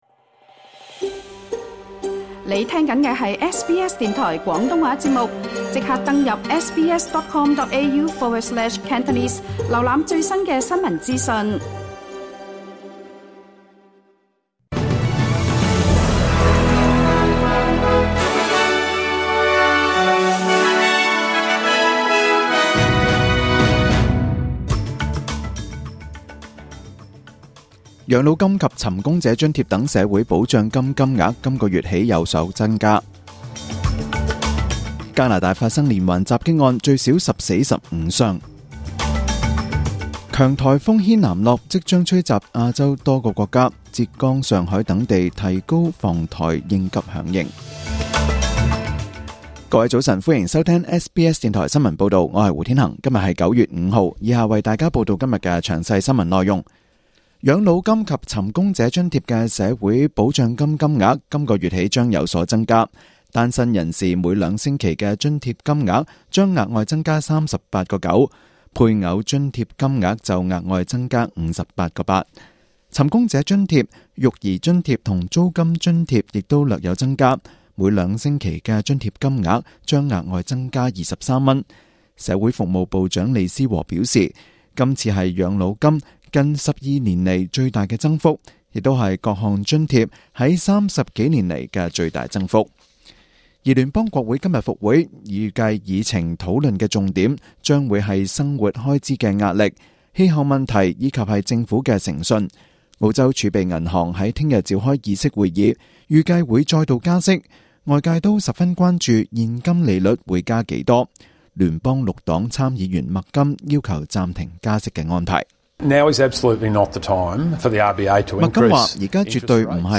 SBS廣東話節目中文新聞 Source: SBS / SBS Cantonese